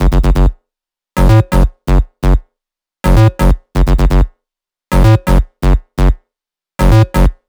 VFH1 128BPM Northwood Melody 1.wav